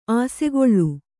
♪ āsegoḷḷu